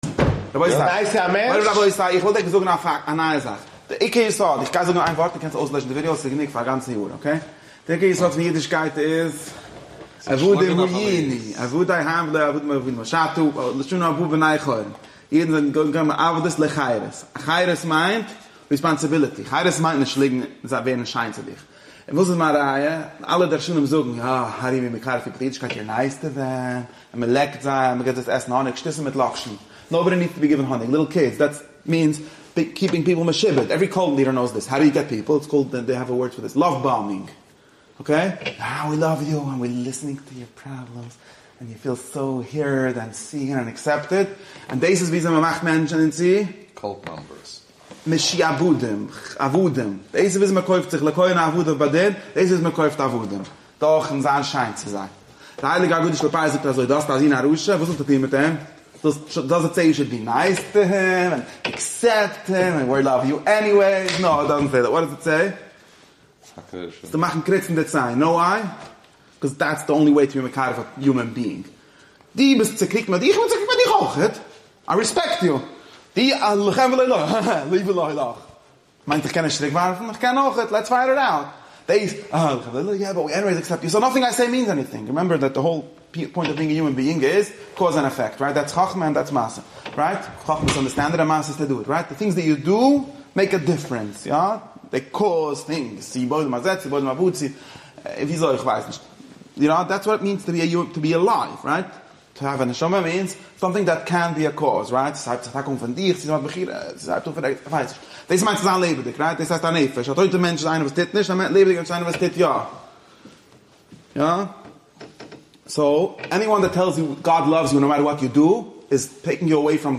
שיעור ליל שישי פרשת ויקרא תשפ”ה